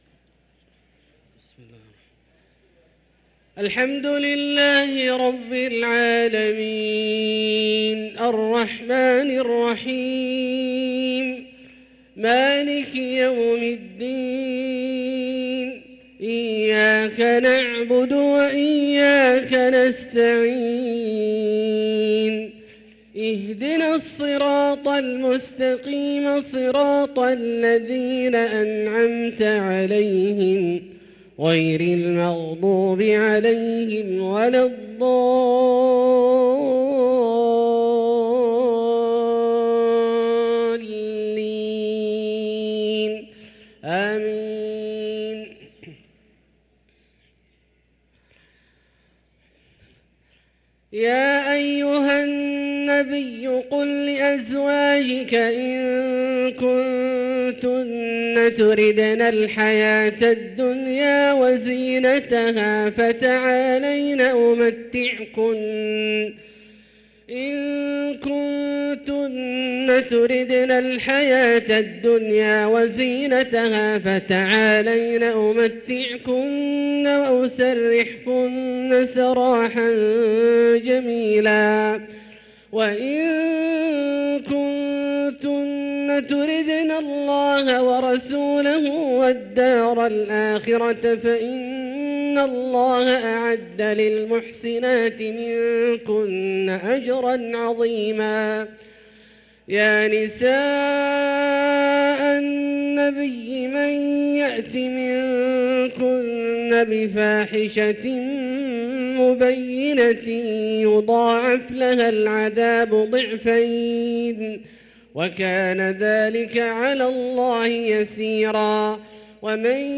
صلاة العشاء للقارئ عبدالله الجهني 10 جمادي الآخر 1445 هـ